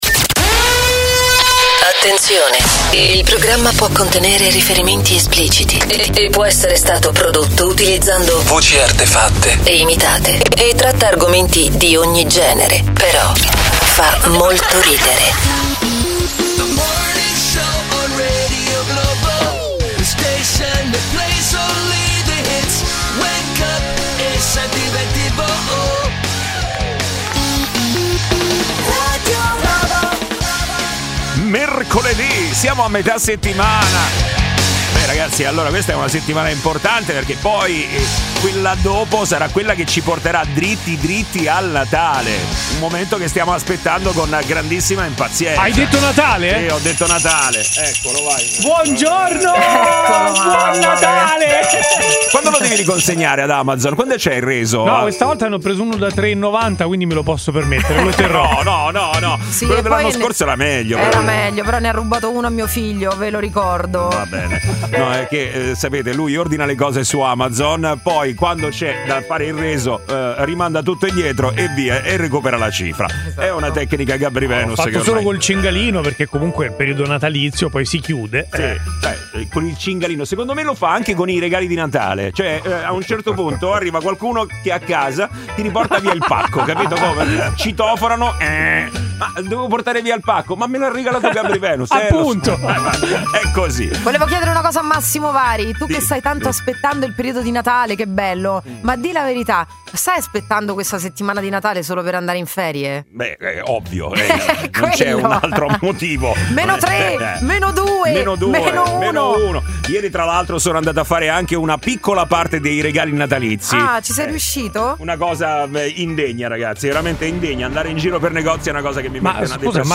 Umorismo, attualità, aggiornamenti sul traffico in tempo reale e l'immancabile contributo degli ascoltatori di Radio Globo, protagonisti con telefonate in diretta e note vocali da Whatsapp.